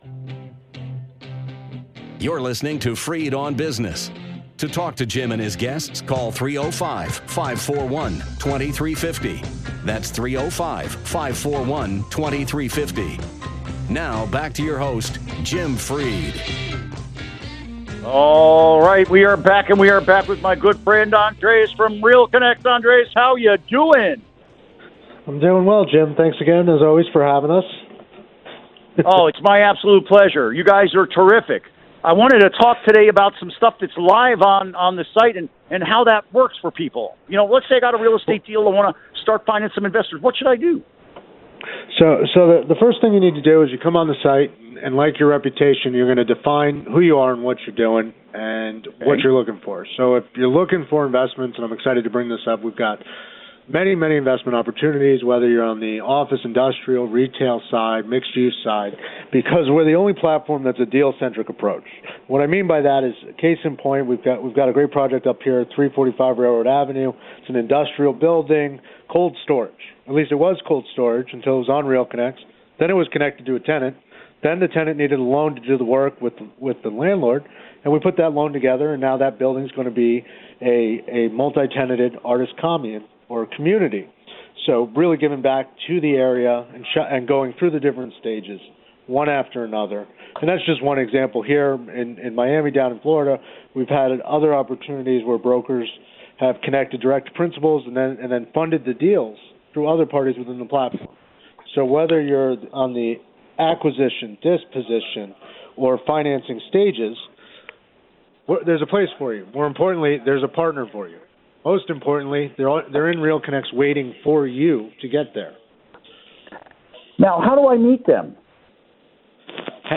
Interview Segment Click here to download Part 1 (To download, right-click and select “Save Link As”.)